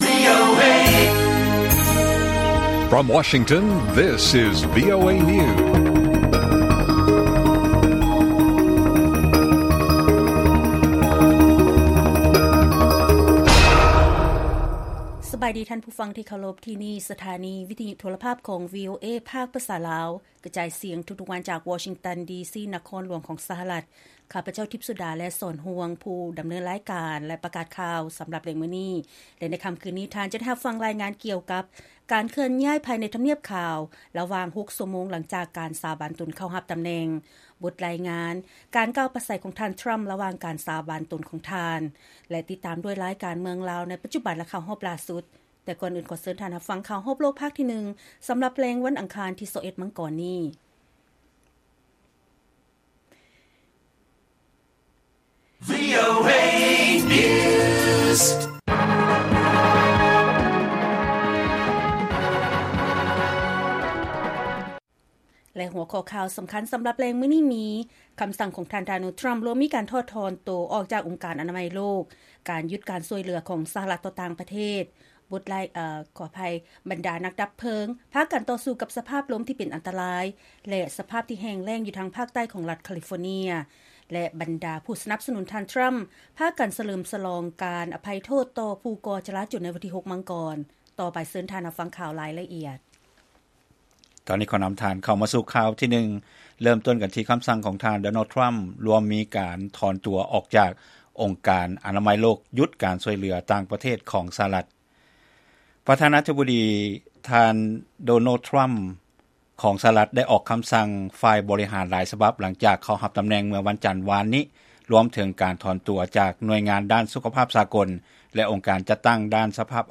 ລາຍການກະຈາຍສຽງຂອງວີໂອເອລາວ: ຄໍາສັ່ງຂອງ ທ່ານ ດໍໂນລ ທຣໍາ ລວມມີ ການຖອນຕົວຈາກອົງການອະນາໄມໂລກ ແລະຢຸດການຊ່ວຍເຫຼືອຂອງ ສະຫະລັດ ຕໍ່ຕ່າງປະເທດ